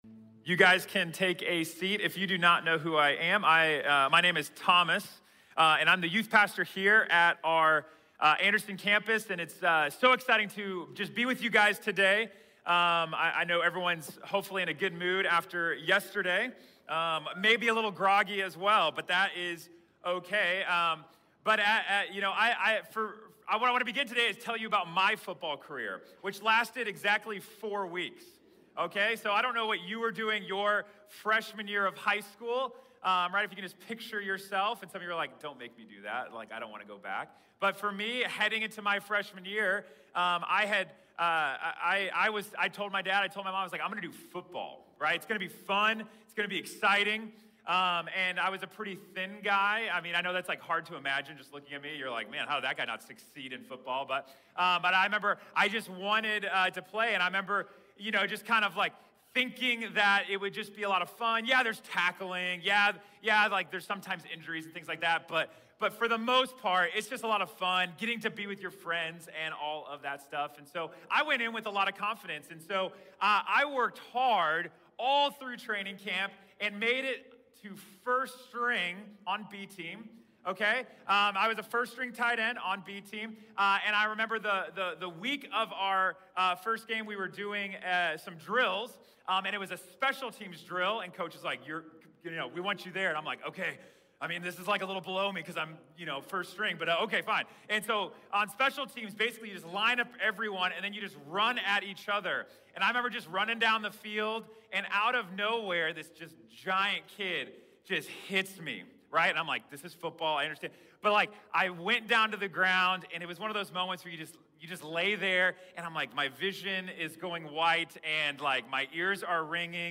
The Flock | Sermon | Grace Bible Church